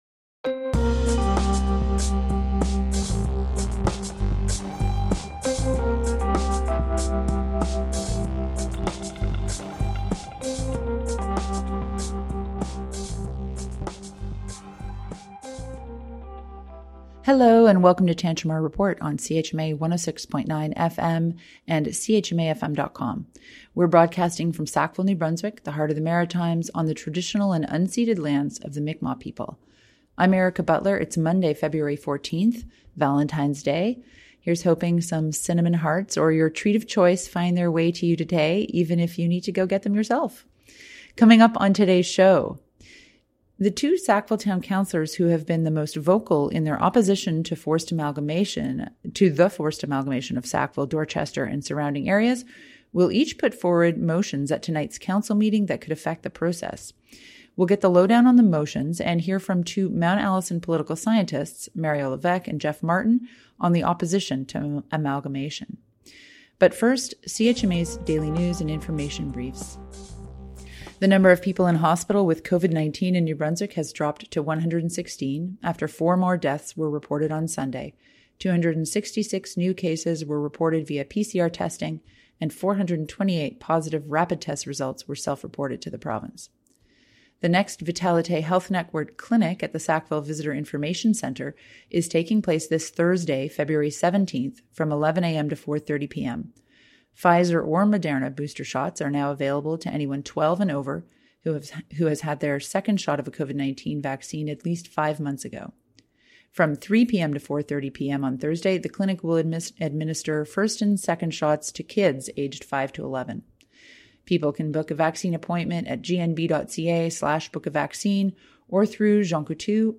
Plus, CHMA’s daily news and information briefs: COVID-19 and vaxx clinic update